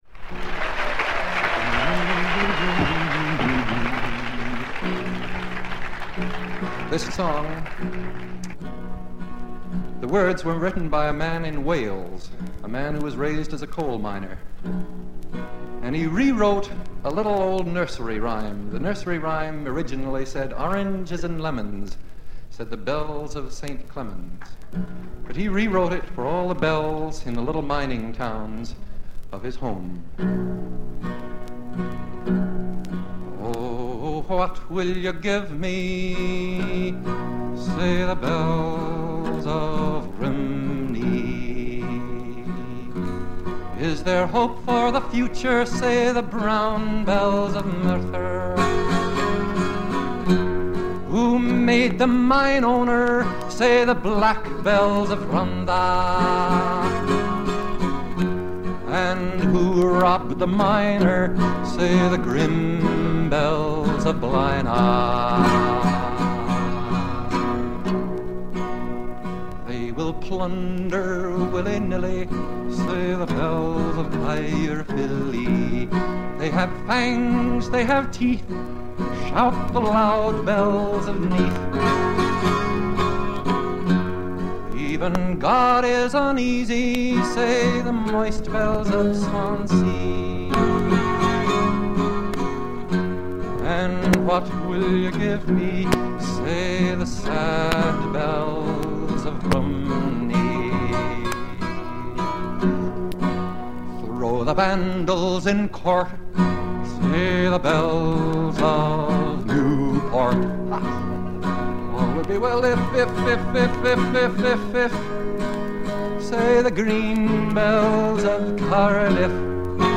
banjo